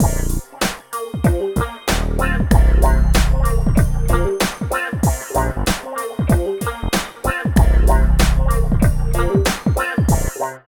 90 LOOP   -L.wav